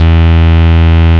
73.01 BASS.wav